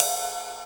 • Ride Cymbal F Key 08.wav
Royality free ride single shot tuned to the F note. Loudest frequency: 6820Hz
ride-cymbal-f-key-08-ZNG.wav